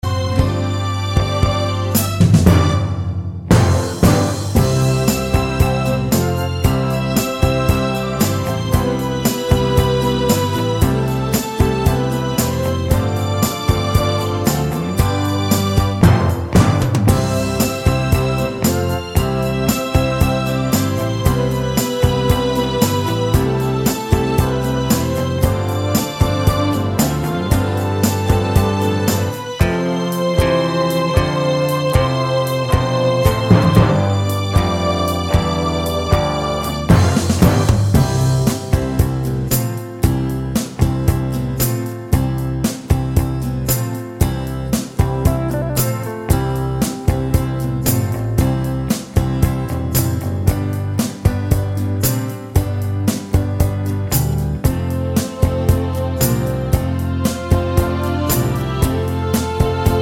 no Backing Vocals Country (Male) 3:27 Buy £1.50